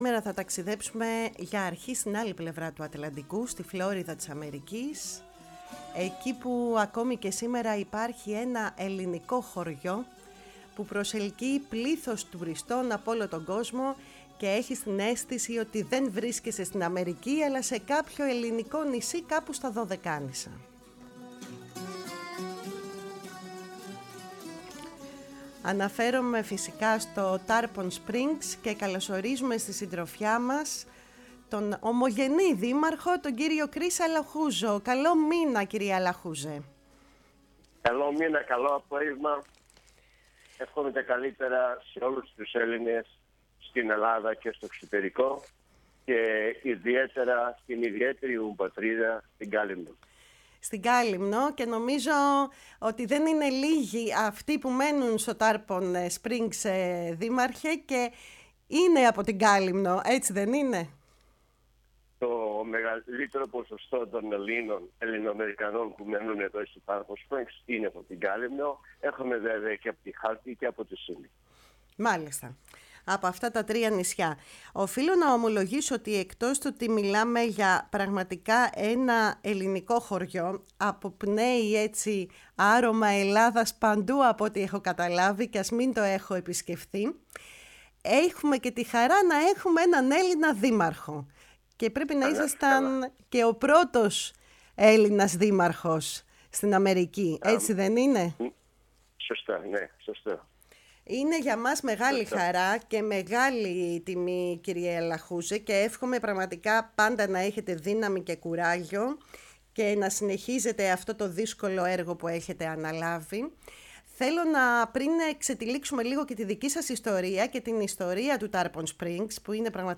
Ο ομογενής δήμαρχος του Τάρπον Σπρινγκς Κρις Αλαχούζος στη “Φωνή της Ελλάδας”